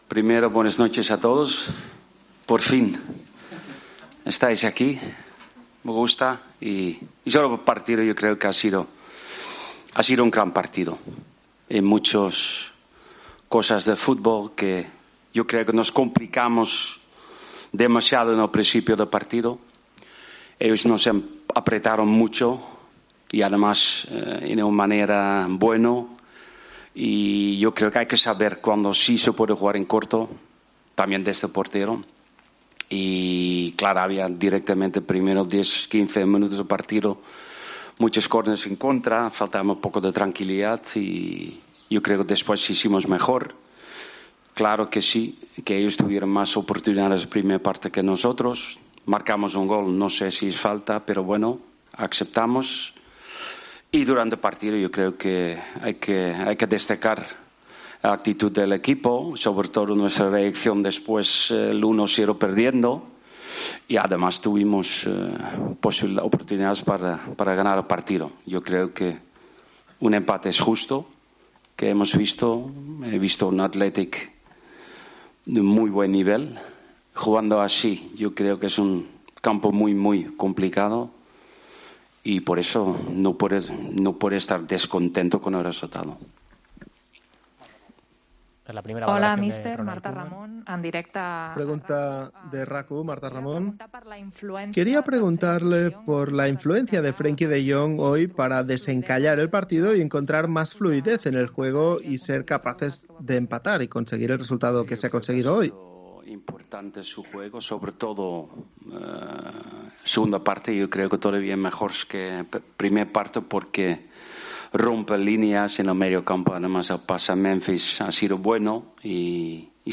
El entrenador del Barcelona valoró en rueda de prensa el empate de los azulgranas en San Mamés: "Ha sido un gran partido. Nos complicamos demasiado al principio".